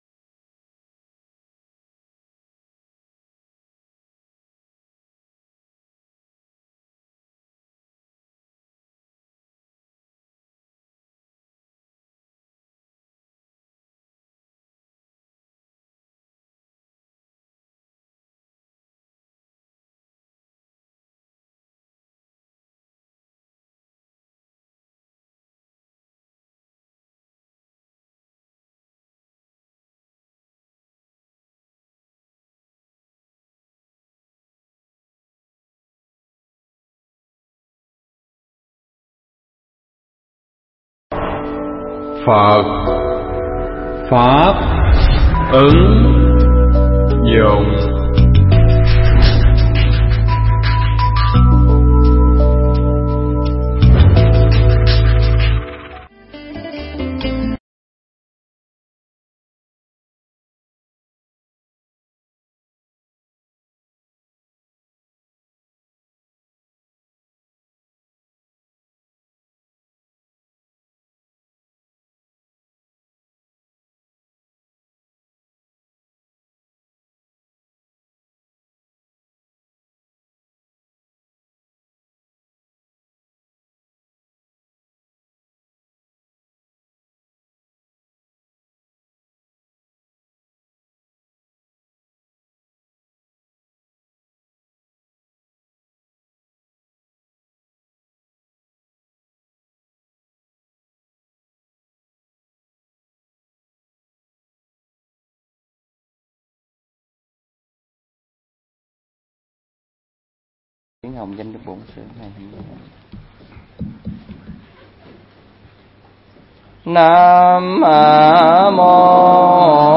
Mp3 Pháp Thoại Người Tu Tịnh Độ Cần Biết